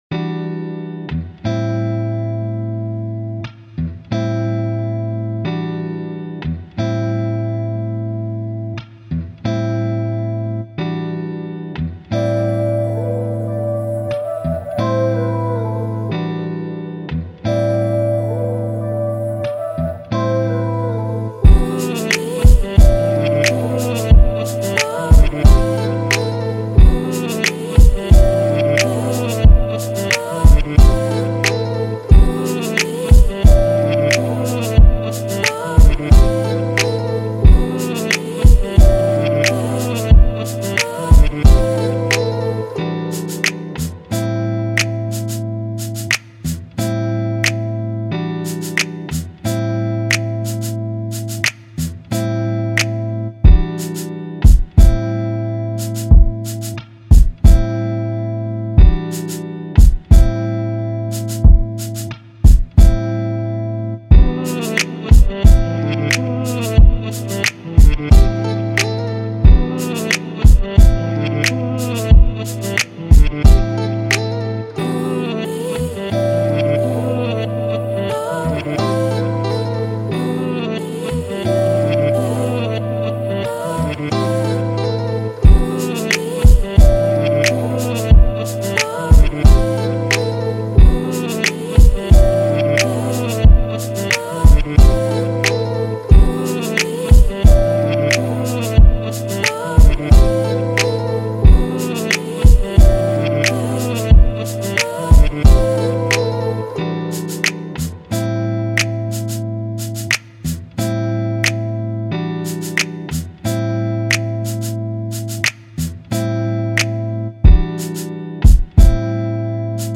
Rap Instrumental